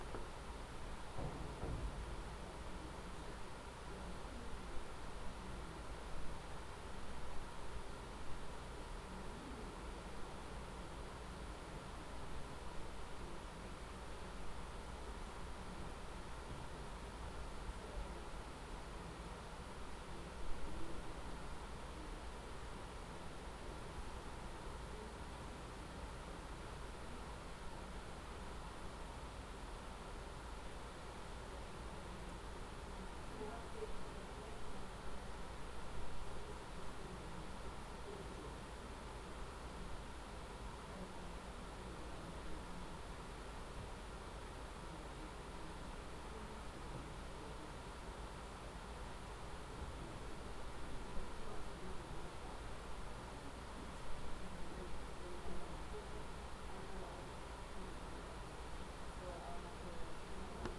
Example One – ALRC Room 103A City-County Building
Before the meeting started various members were discussing the loud fan noise in the room.  Someone described it like when you’re on a plane, and that has stuck with me.
Here’s a clip of the noise in that room:
noisy-room-.wav